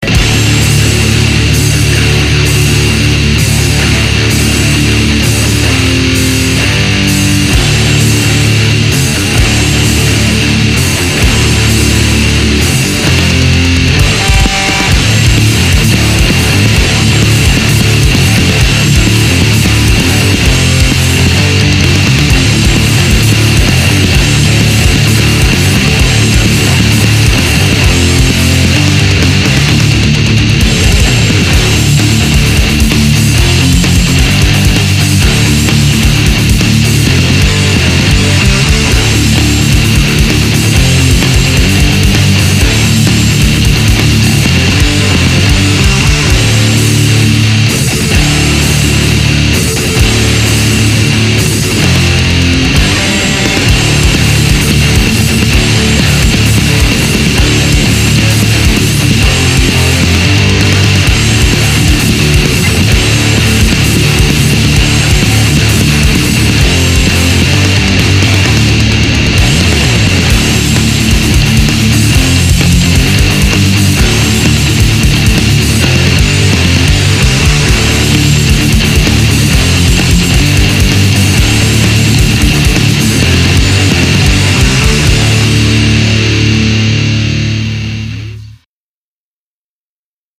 【用途/イメージ】　エクストリーム　スポーツ　バイク　アクション　筋トレ
130BPM　バンドサウンド　ギターリフ　ギターソロなし